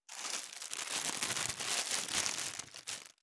609コンビニ袋,ゴミ袋,スーパーの袋,袋,買い出しの音,ゴミ出しの音,袋を運ぶ音,
効果音